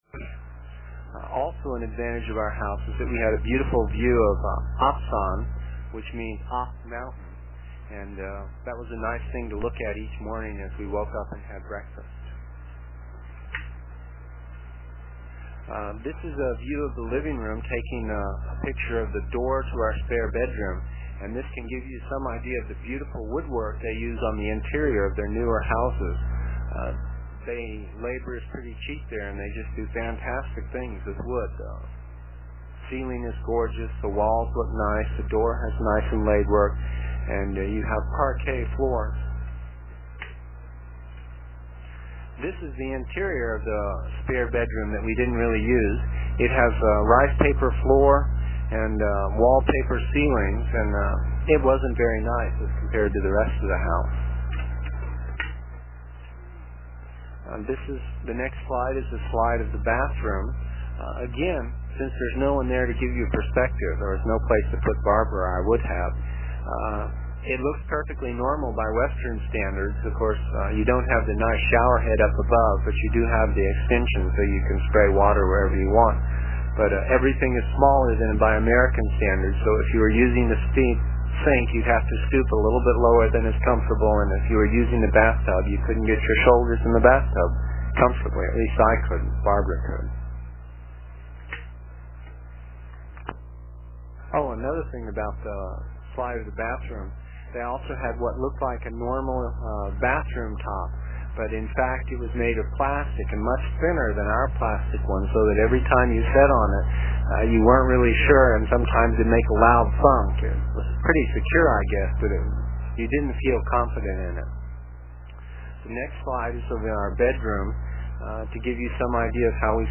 It is from the cassette tapes we made almost thirty years ago. I was pretty long winded (no rehearsals or editting and tapes were cheap) and the section for this page is about six minutes and will take about two minutes to download with a dial up connection.